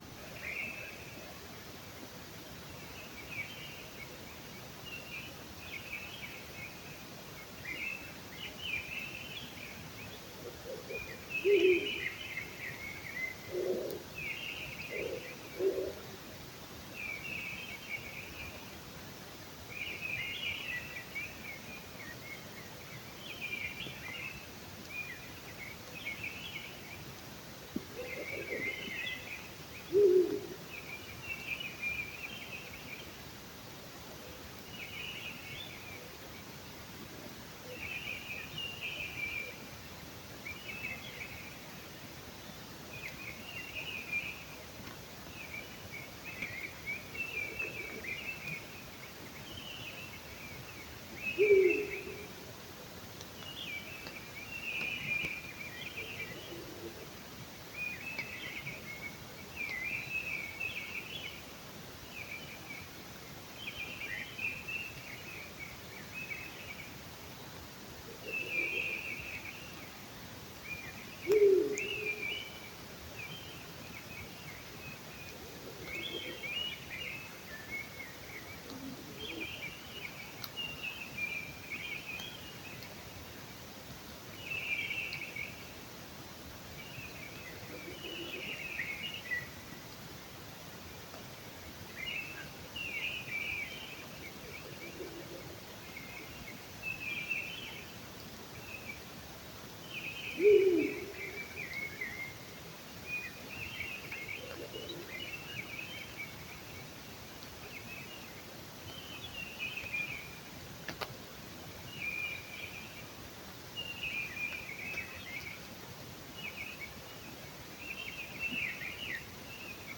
Birds -> Owls ->
Ural Owl, Strix uralensis
StatusPair observed in suitable nesting habitat in breeding season